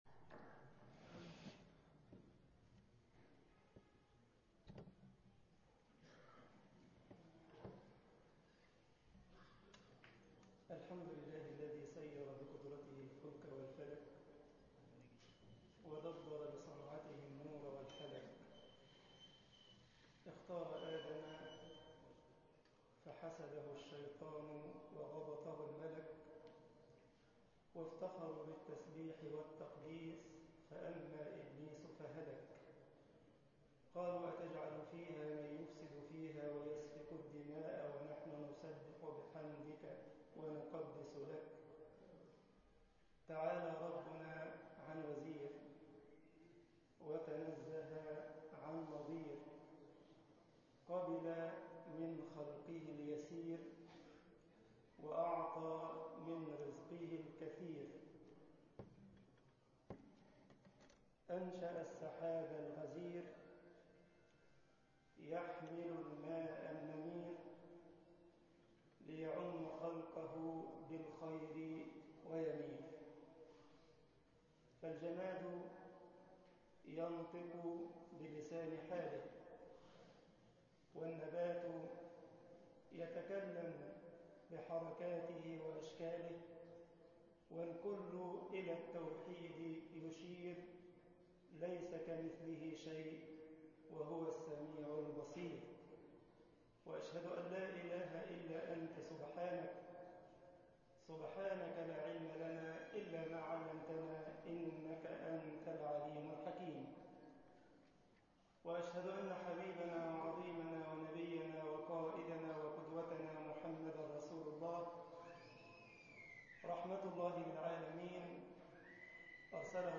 مسجد نور الاسلام ـ عين شمس محاضرة